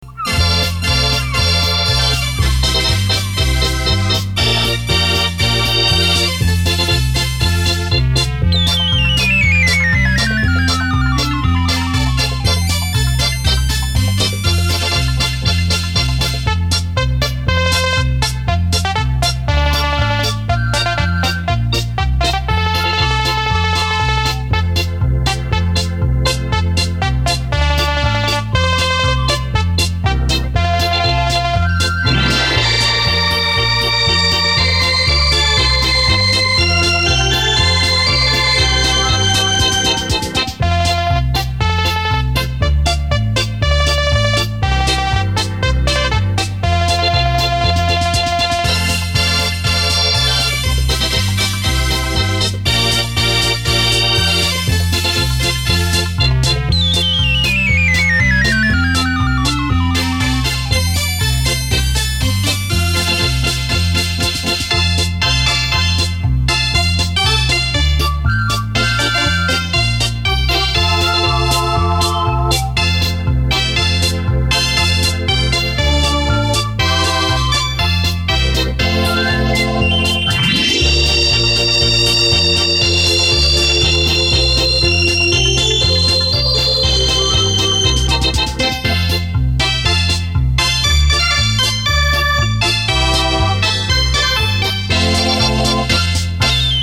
乐曲